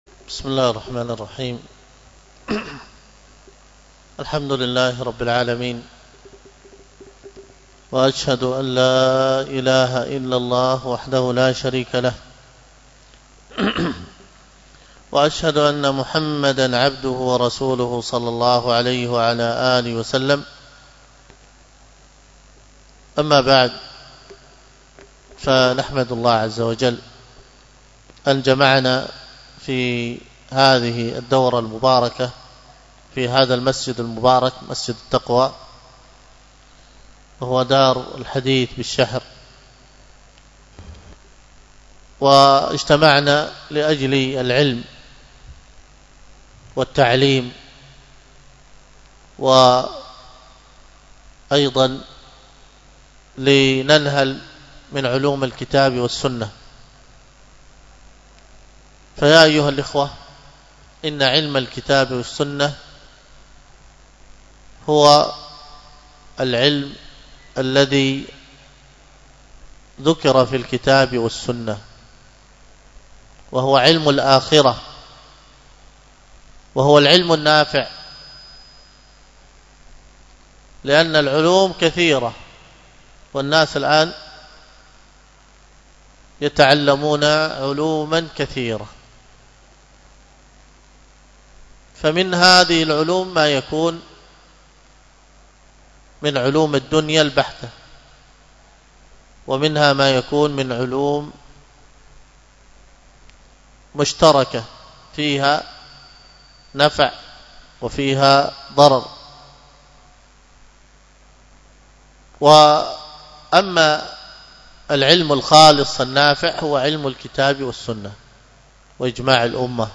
المحاضرة بعنوان كيف يكون العلم نافعا ؟، والتي كانت ضمن الدورة العلمية الثالثة والعشرين بمسجد التقوى بدار الحديث بالشحر